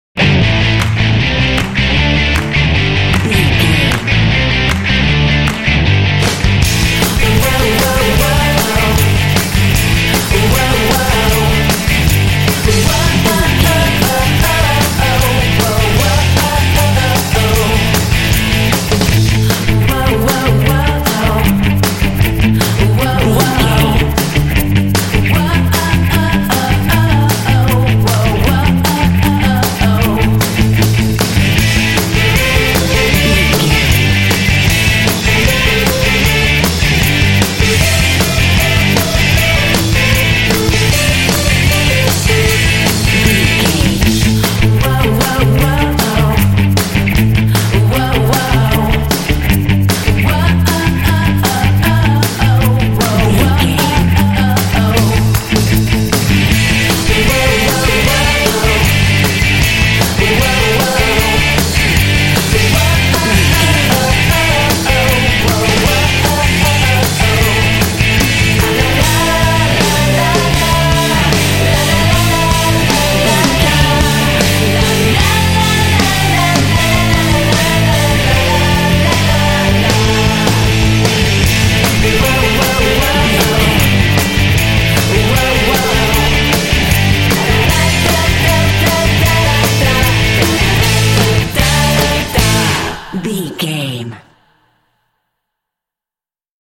This Pop track is full of energy and drive.
Ionian/Major
driving
energetic
electric guitar
bass guitar
drums
electric piano
vocals
pop